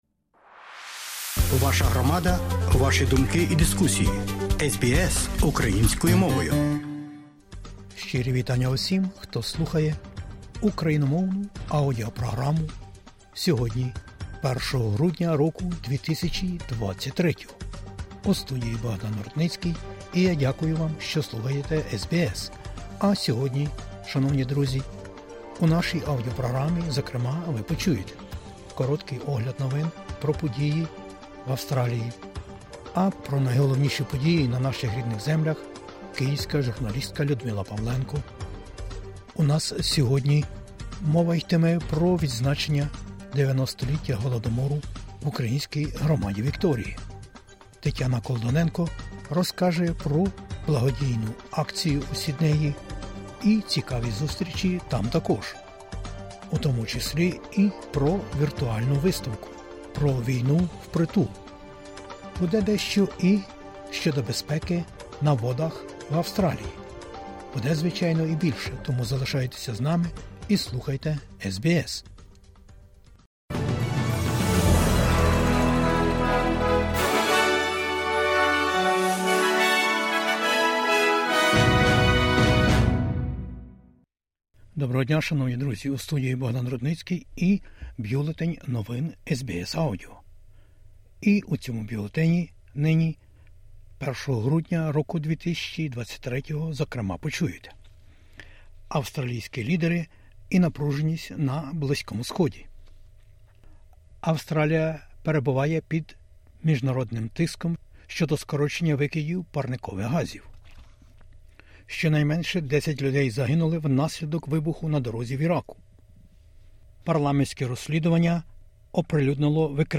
Про найважливіше із потоку новин в Австралії, Україні та світі на час виходу радіовісника SBS новин українською. Політичні лідери Австралії закликали австралійців зібратися разом і проявлятити співчуття вдома, оскільки напруженість продовжує кипіти через Близький Схід.